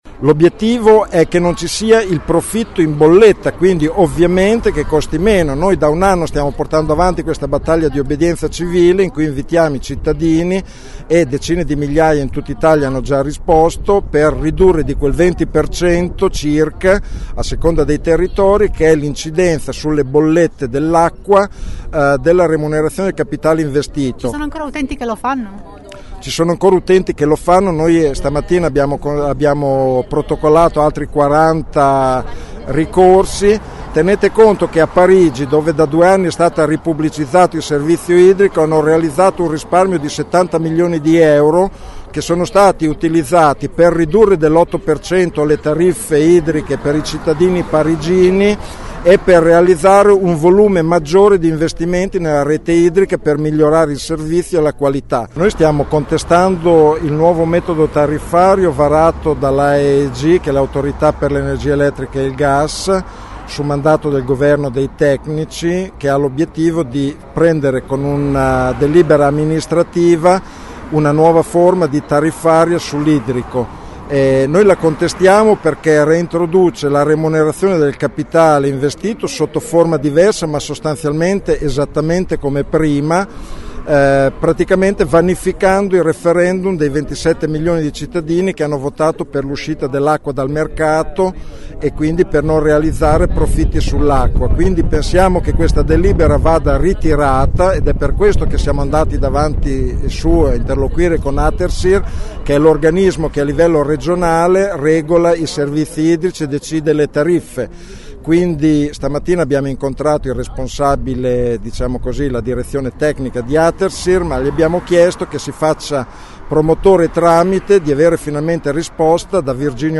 25 gen. – Questa mattina i comitati bolognesi per l’acqua pubblica hanno fatto un doppio presidio per protestare contro la nuova tariffa dell’acqua.